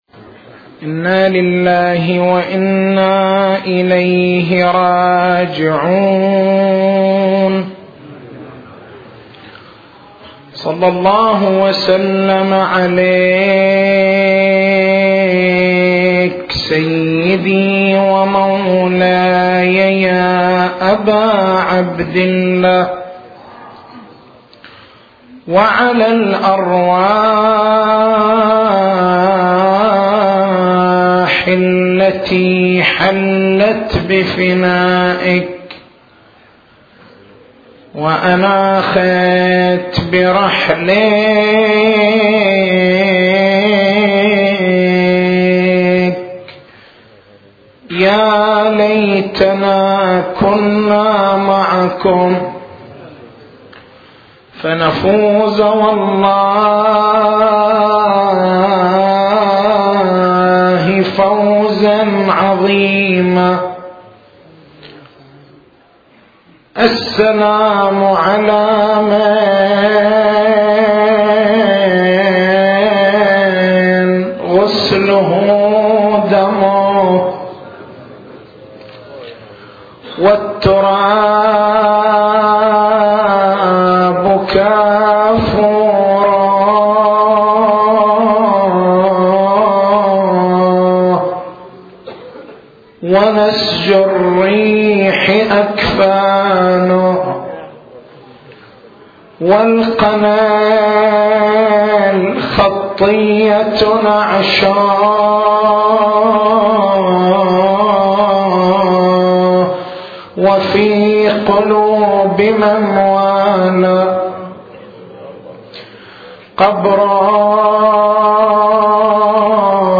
تاريخ المحاضرة: 13/01/1428 نقاط البحث: الزاوية التاريخية الإمام زين العابدين (ع) أم بنو أسد؟